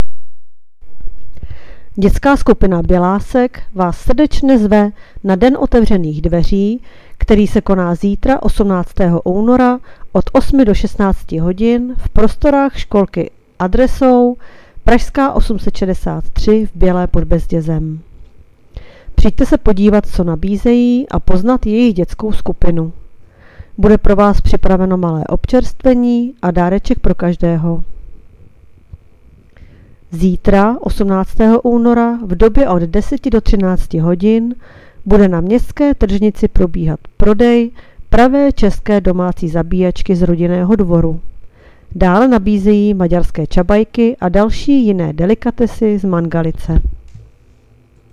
Hlášení městského rozhlasu 17.2.2025